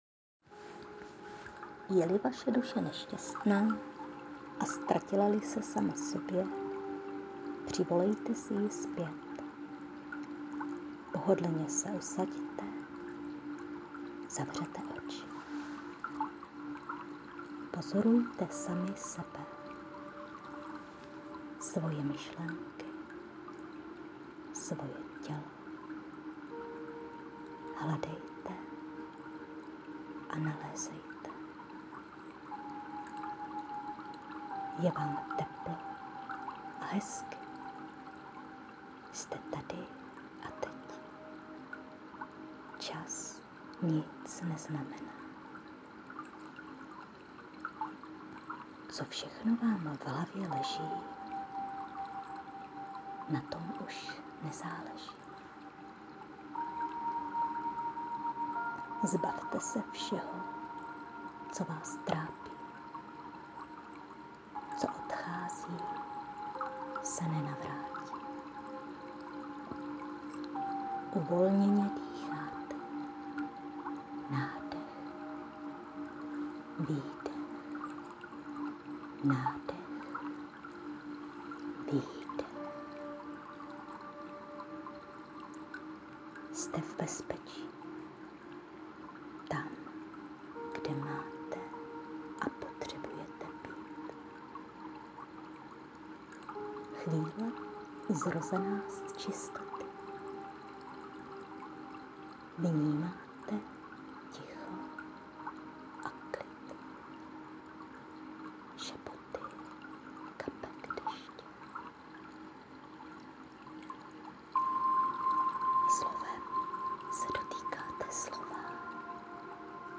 Uklidňující a pohodové odpočinkové.
Krásná, jemná, něžná meditace ;-).